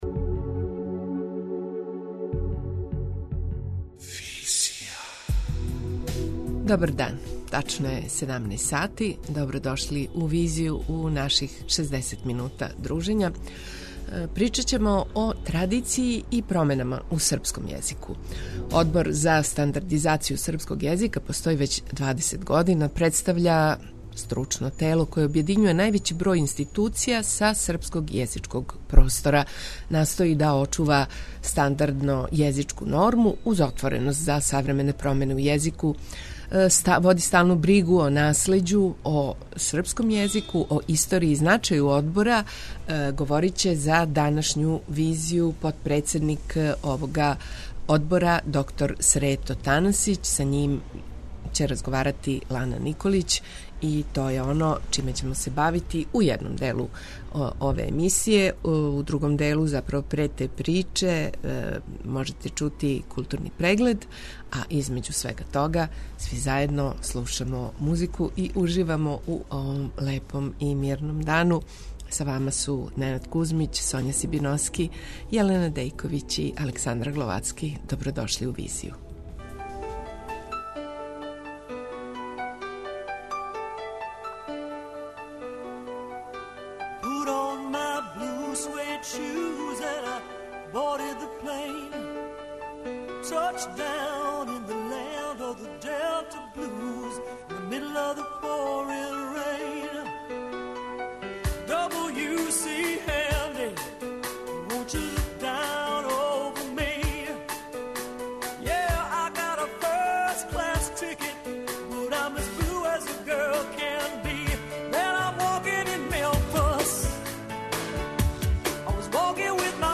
преузми : 28.22 MB Визија Autor: Београд 202 Социо-културолошки магазин, који прати савремене друштвене феномене.